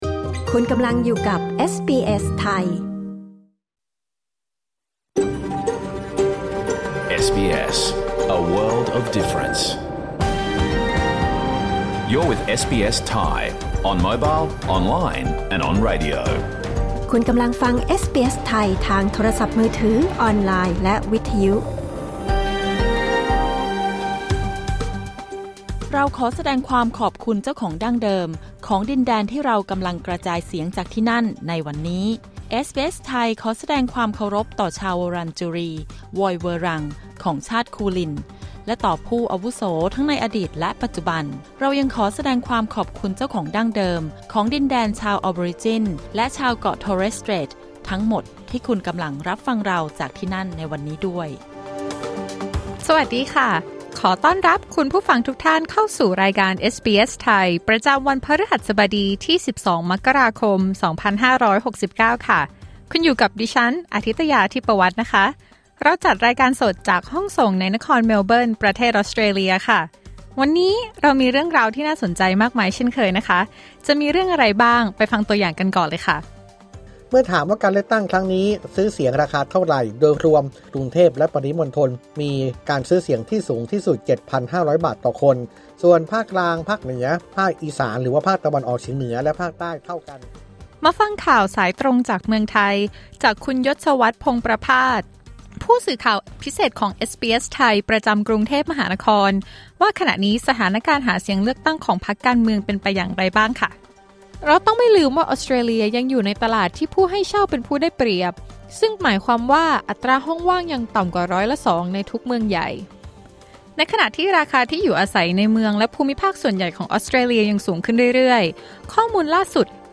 รายการสด 22 มกราคม 2569